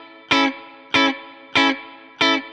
DD_StratChop_95-Amaj.wav